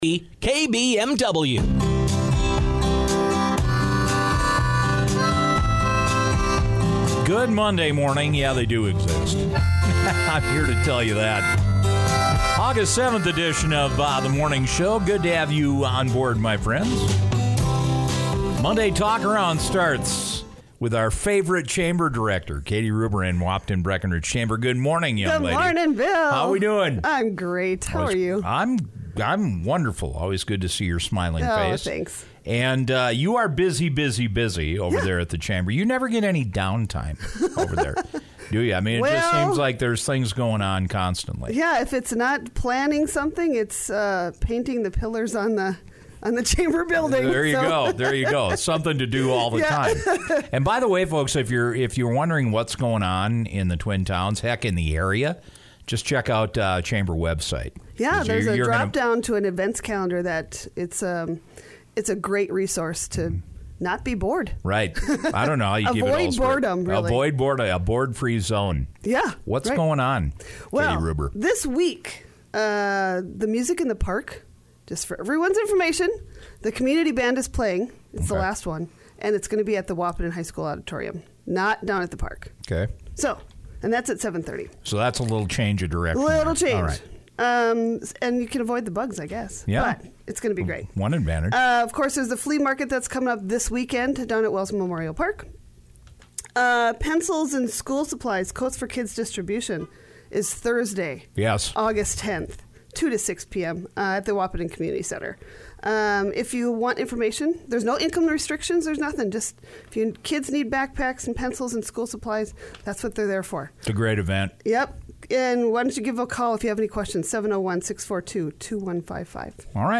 radio segment